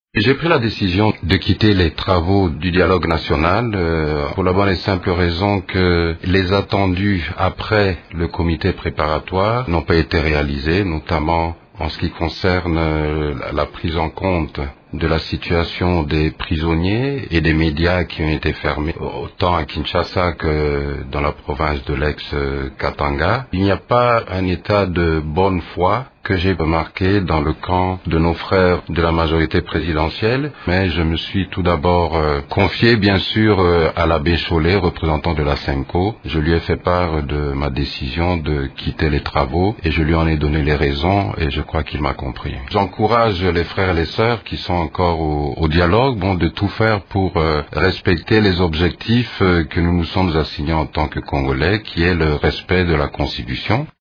Dans cet extrait sonore, il demande aux délégués qui poursuivent les travaux « de tout faire pour respecter les objectifs fixés en tant que Congolais» :